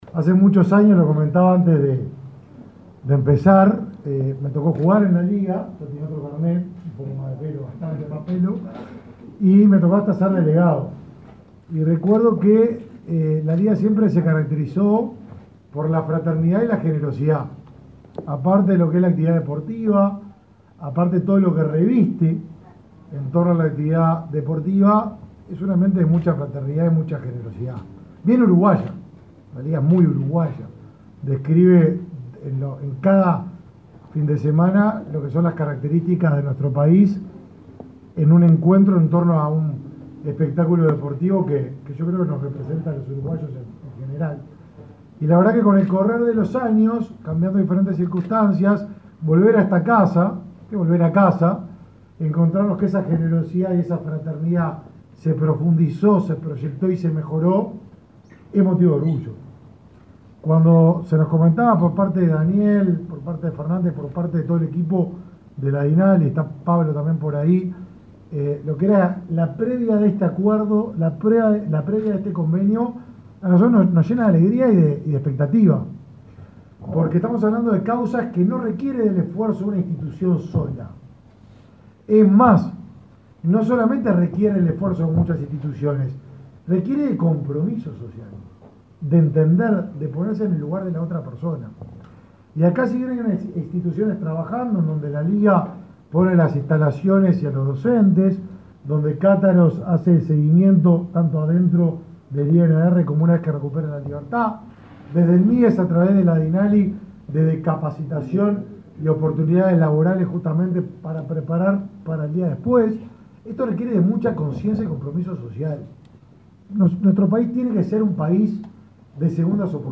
Palabras del ministro de Desarrollo Social, Martín Lema